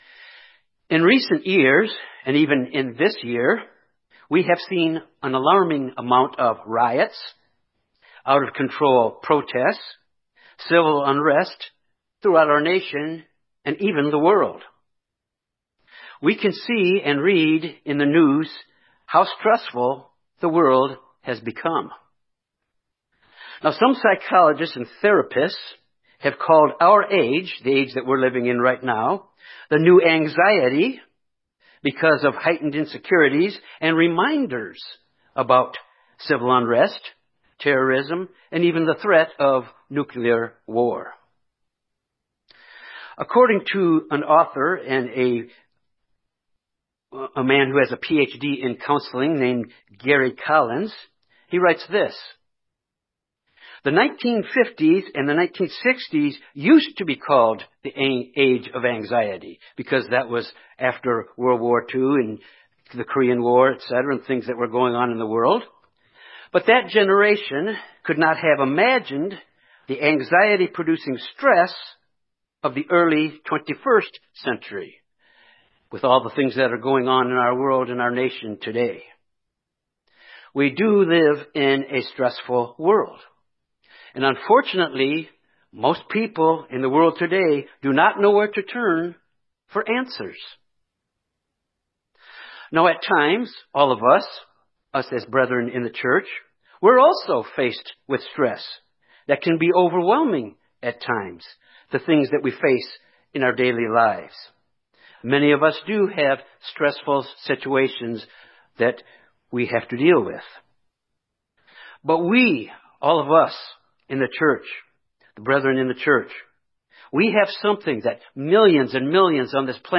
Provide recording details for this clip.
Given in Little Rock, AR Memphis, TN Jonesboro, AR